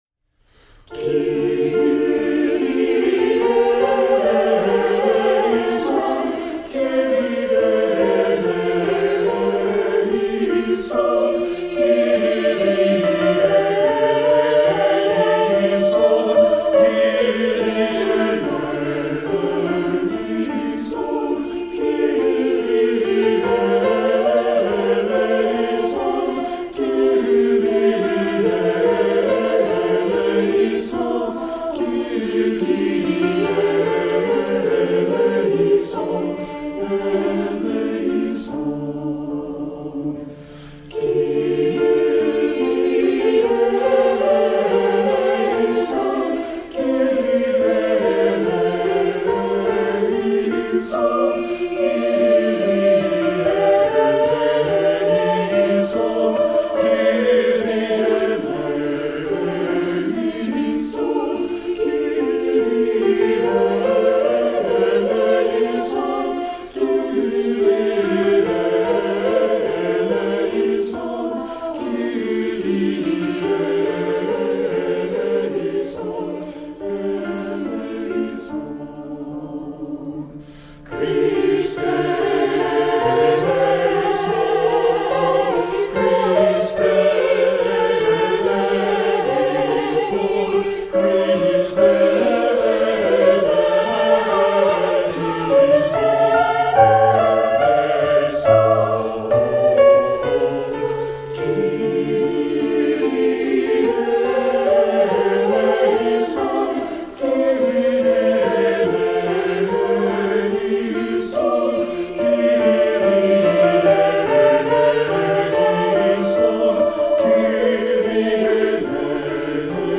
Voicing: SAB a cappella